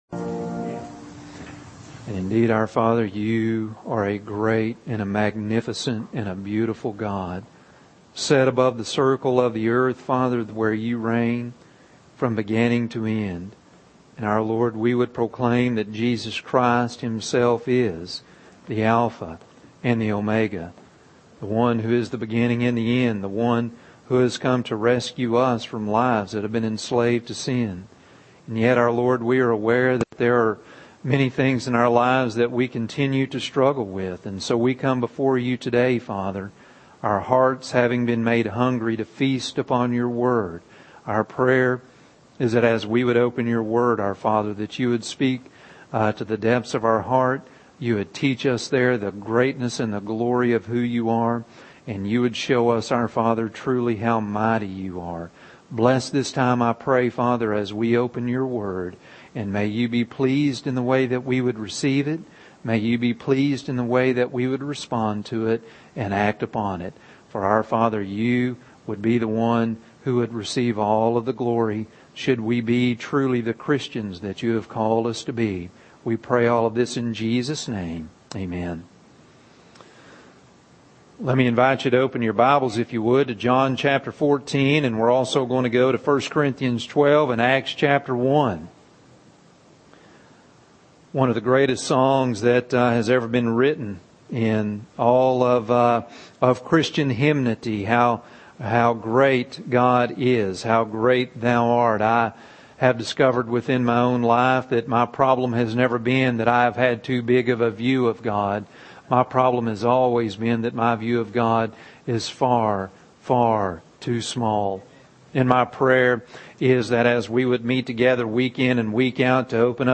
Sermon Podcasts